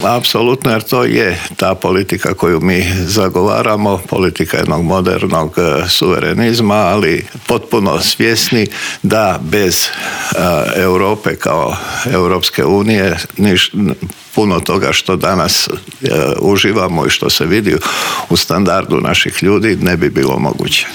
ZAGREB - Koji je najizgledniji datum za parlamentarne izbore, kakva su očekivanja HDZ-a, kao nositelj liste u šestoj izbornoj jedinici želi li još jedan mandat na čelu MUP-a, odgovore smo u Intervjuu tjedna Media servisa potražili od potpredsjednika Vlade i ministra unutarnjih poslova Davora Božinovića, koji nam prokomentirao i Zakon o strancima, ali i kako stojimo s ilegalnim migracijama.